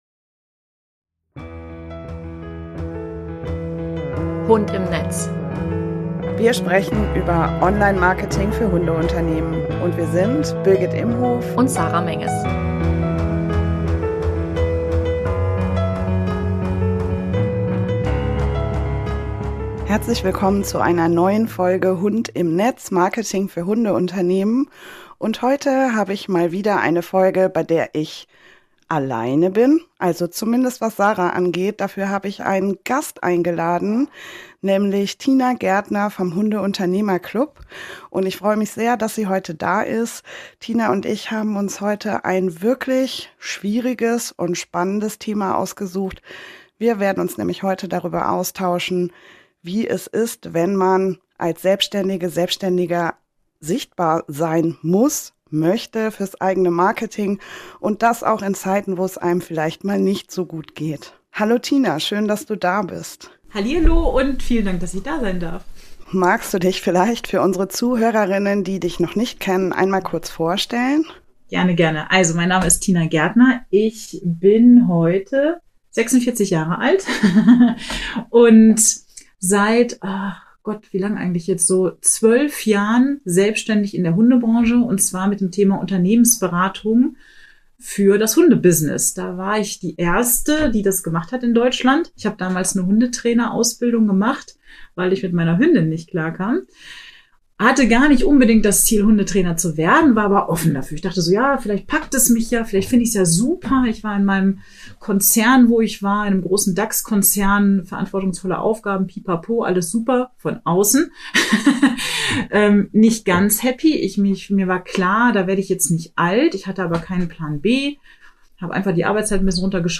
#12 Burnout - Interview